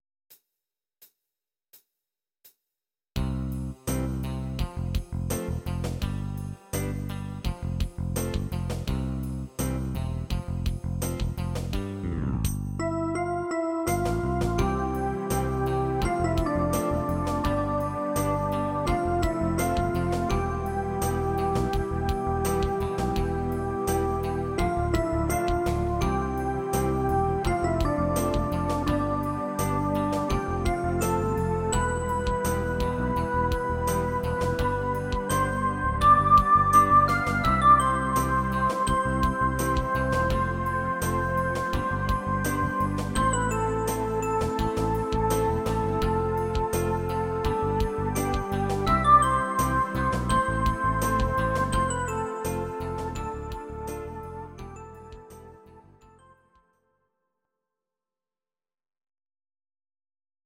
Audio Recordings based on Midi-files
Oldies, Ital/French/Span, Duets, 1960s